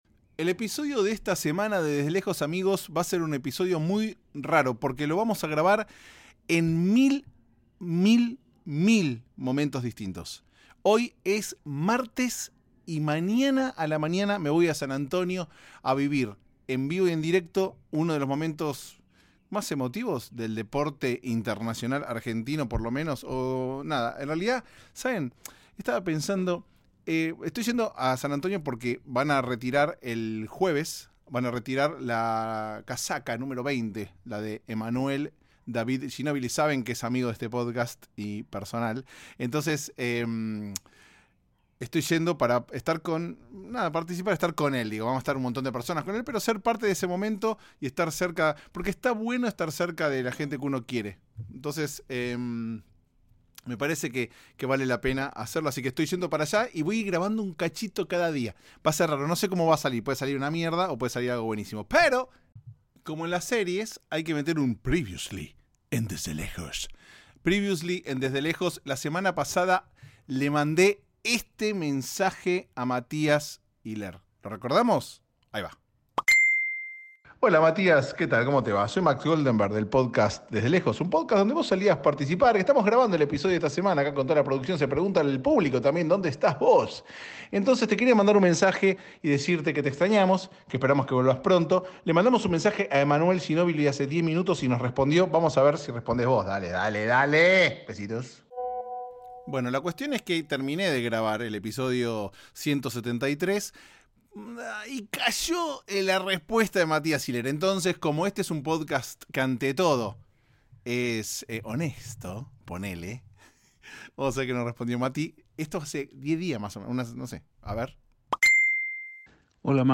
Un episodio grabado a lo largo de una semana espectacular en San Antonio, Texas. Spoiler Alert: le sacaron la camiseta a Ginóbili.